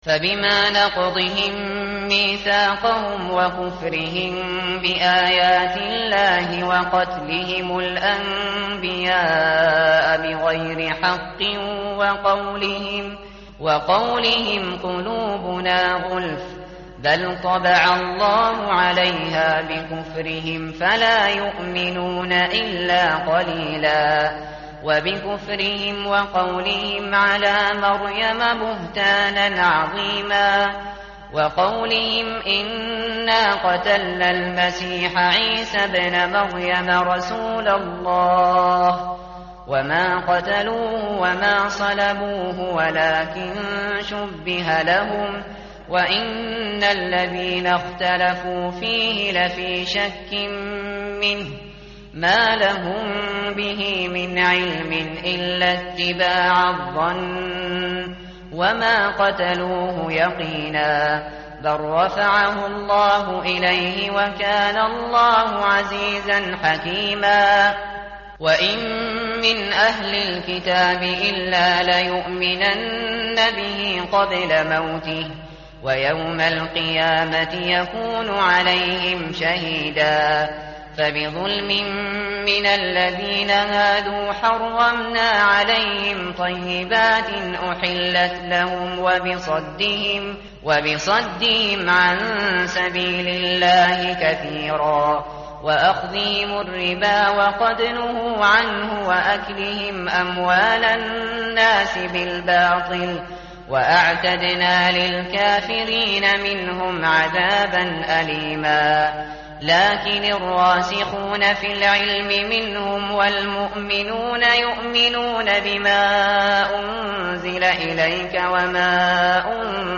tartil_shateri_page_103.mp3